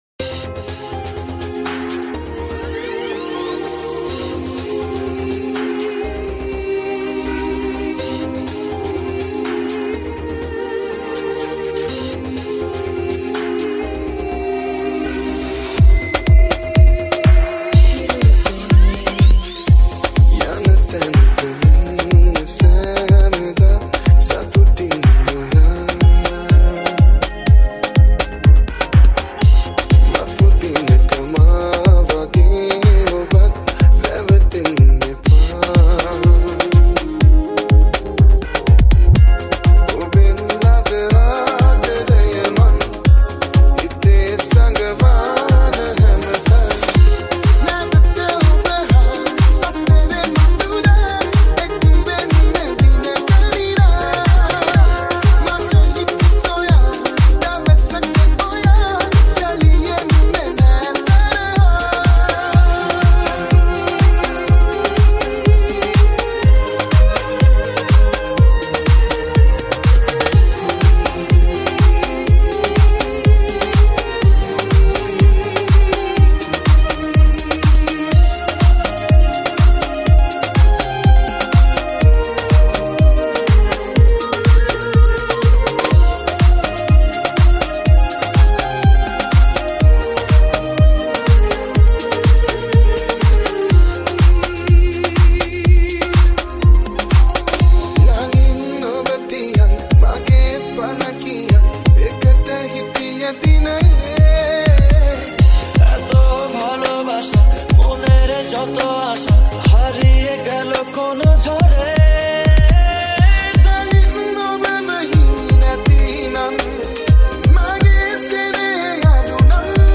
High quality Sri Lankan remix MP3 (3.7).